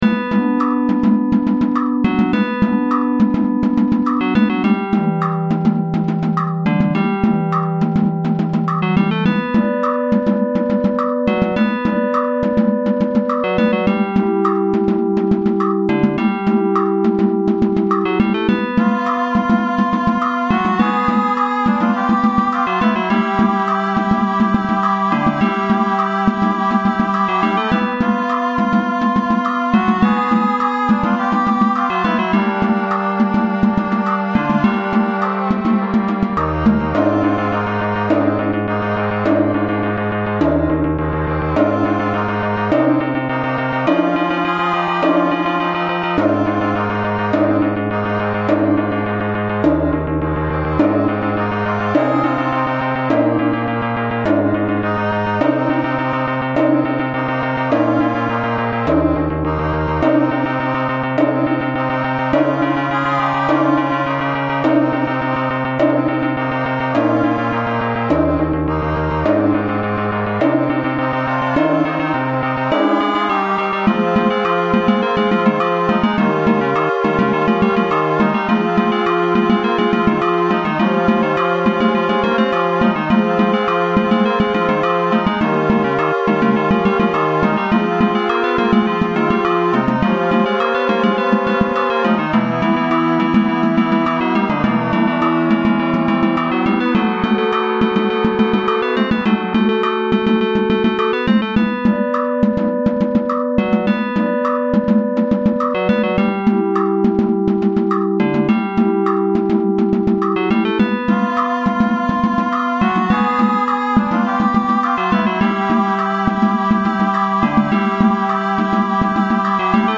The Likely Temporary Alaska BGM